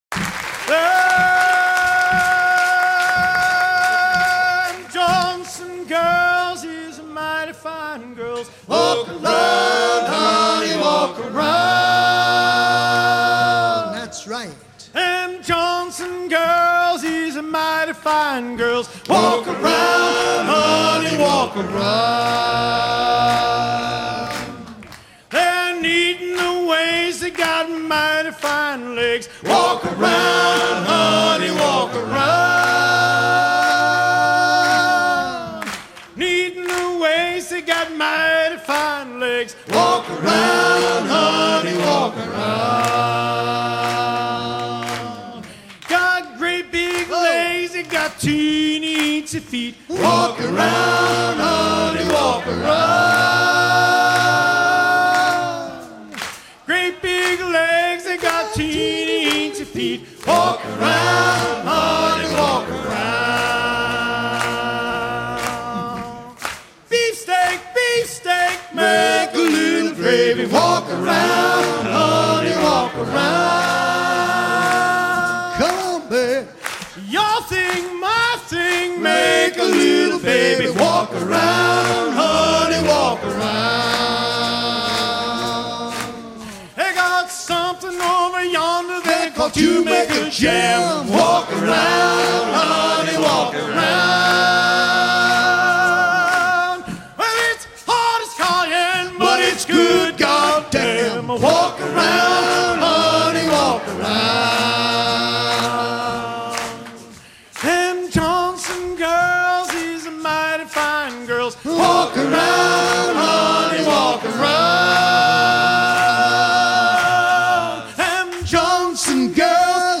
chantey collecté en 1940 auprès de pêcheurs de chinchards de Mayport en Floride
Fonction d'après l'analyste gestuel : à haler
circonstance : maritimes